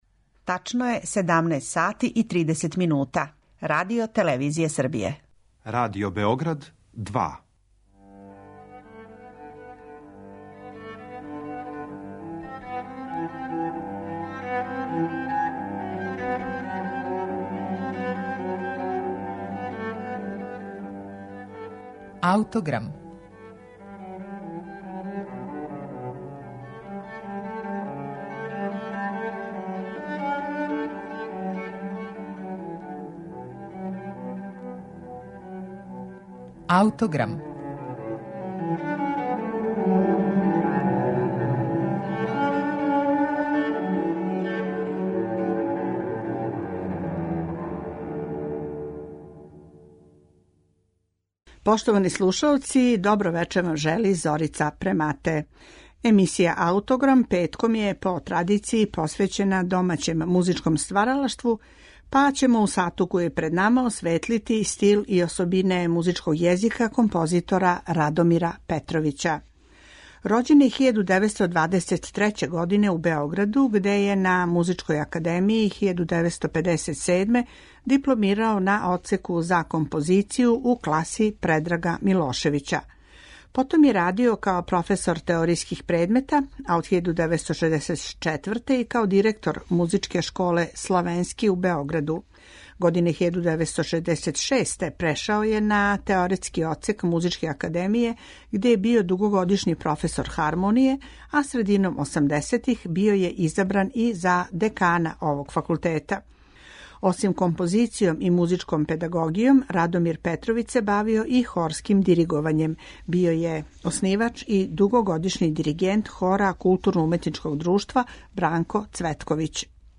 за обоу и гудаче